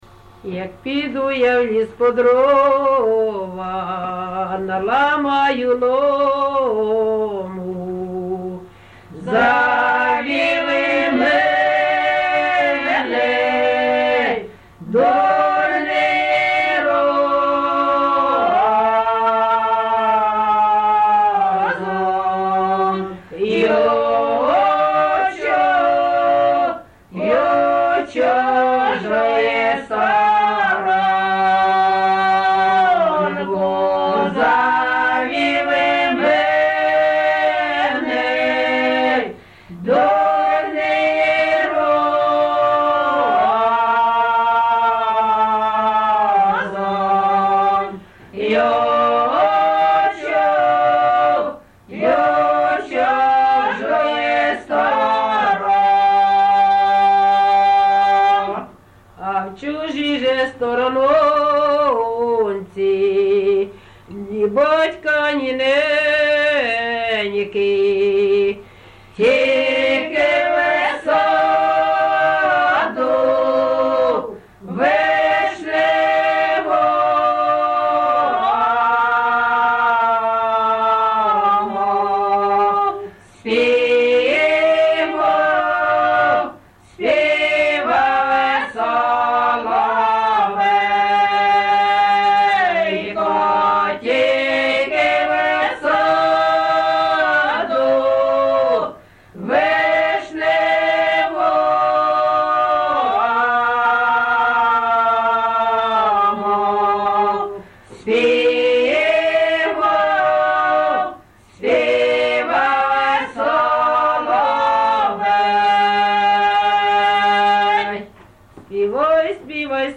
ЖанрПісні з особистого та родинного життя
Місце записус. Почапці, Миргородський район, Полтавська обл., Україна, Полтавщина